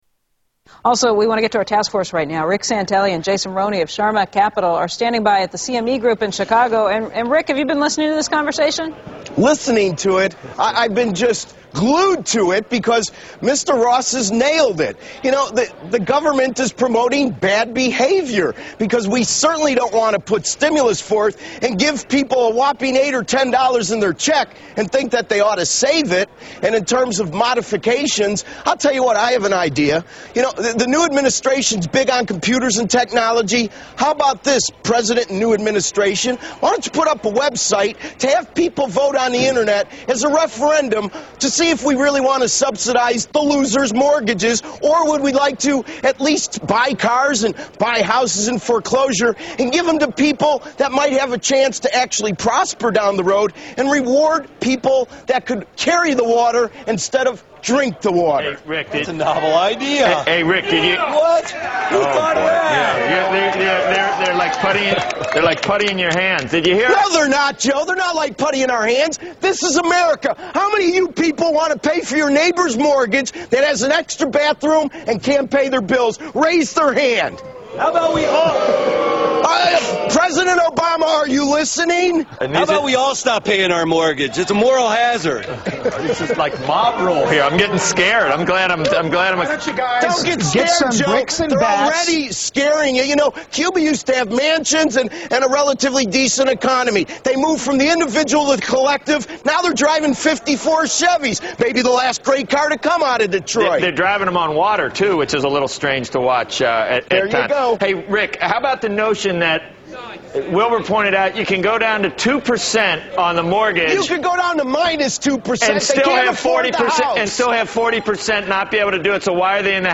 Rant of the year- Rick Santelli